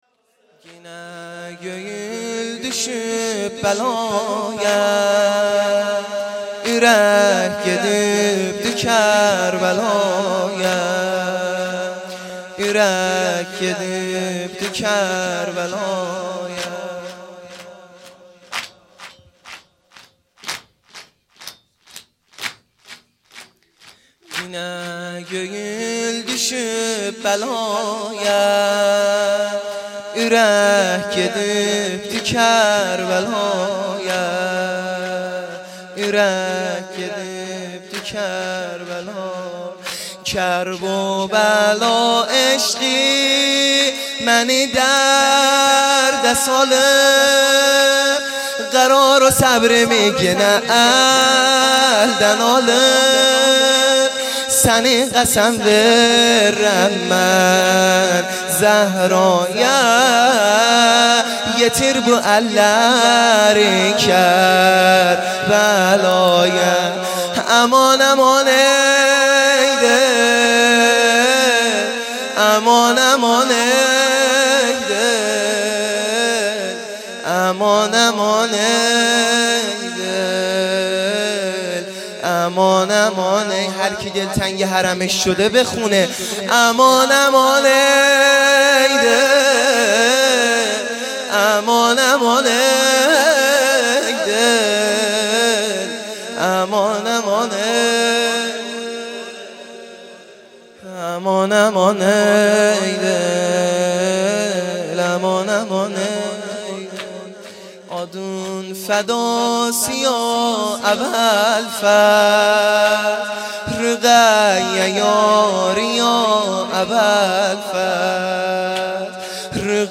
0 0 شور | گینه گویول دوشوب
جلسه هفتگی ( 11 دیماه )